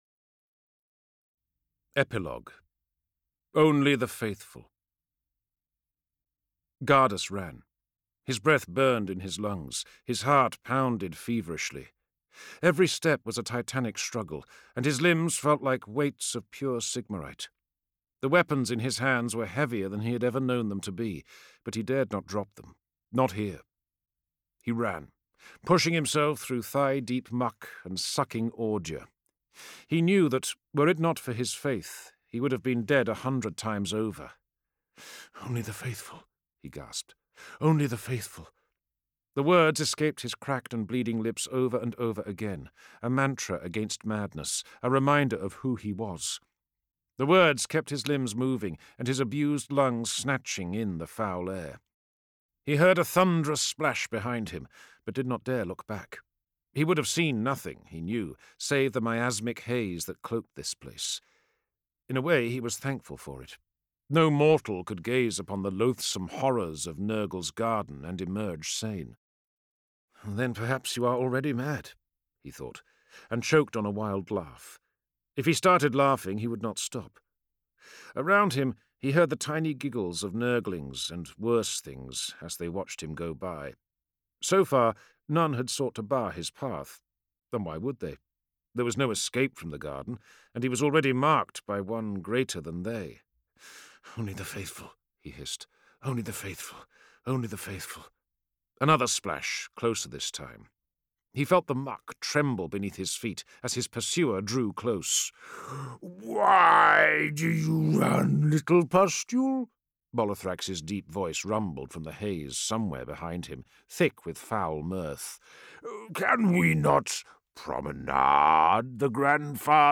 Index of /Games/MothTrove/Black Library/Age of Sigmar/Audiobooks/Realmgate Wars Series/01.